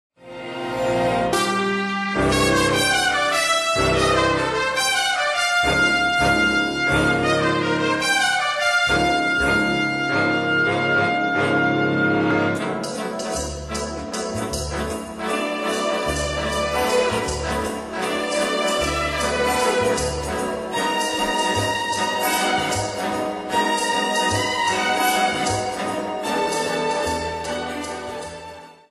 Konzertstück für Big Band und Streicher